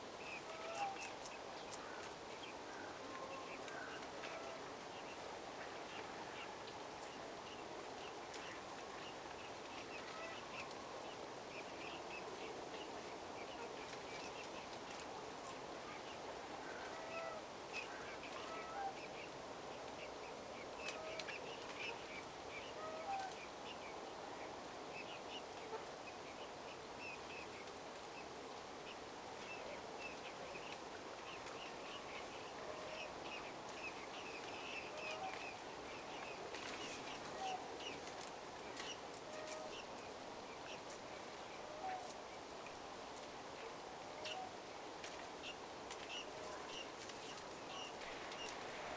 viiksitimalien lähiääntelyä, 2
viiksitimalien_vahan_punatulkkumaisia_aania_lahiaantelya.mp3